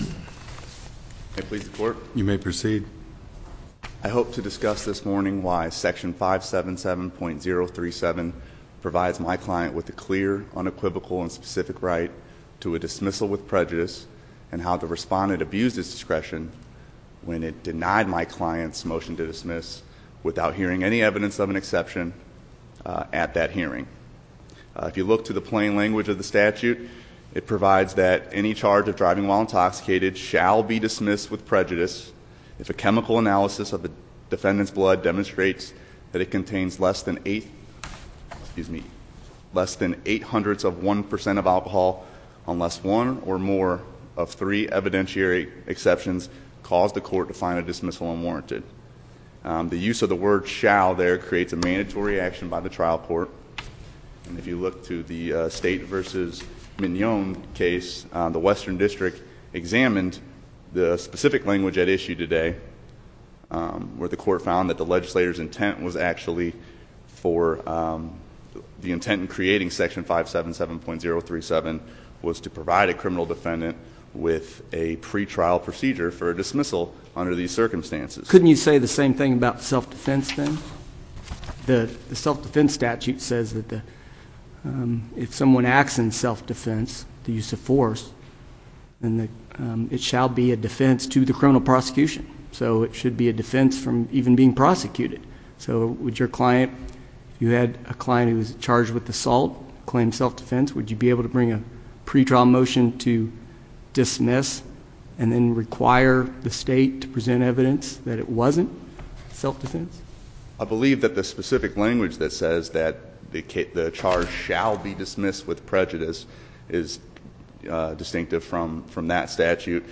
MP3 audio file of arguments in SC97284